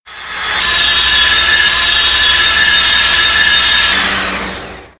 grinder.mp3